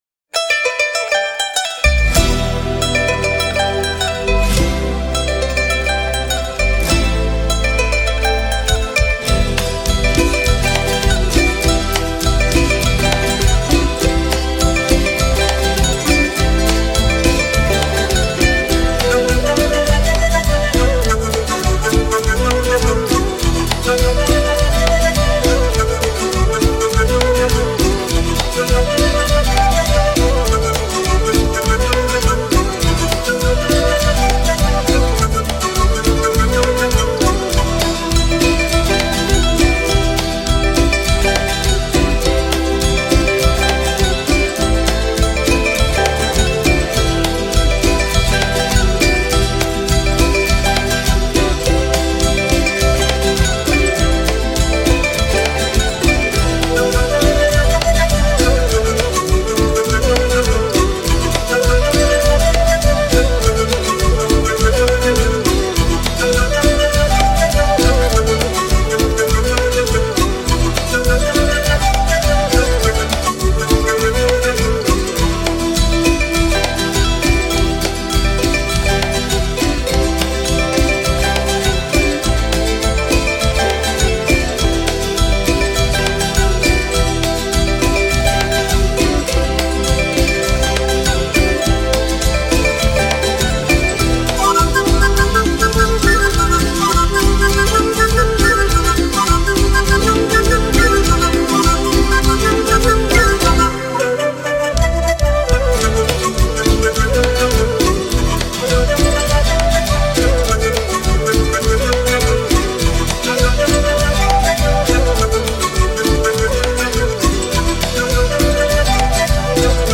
LBfdOI6cmpz_Musica-instrumental-Andina.mp3